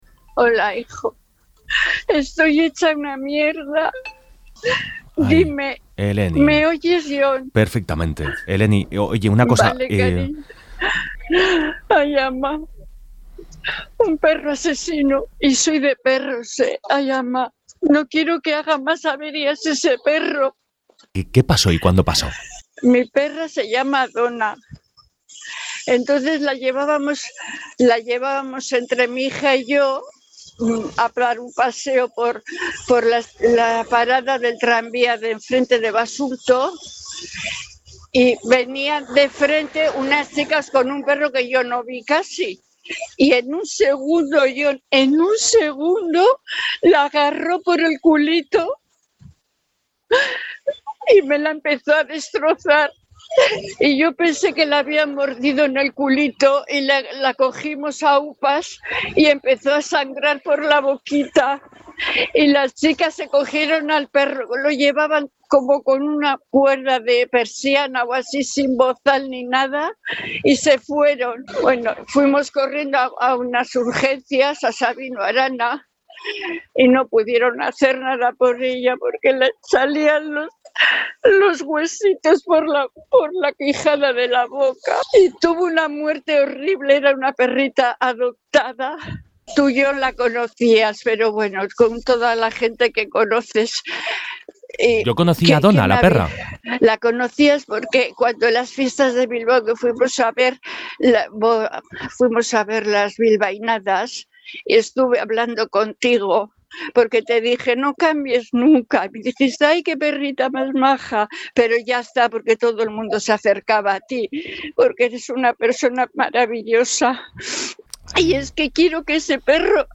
ENTREVISTA | «Llevaban el perro con una cuerda larga, sin bozal».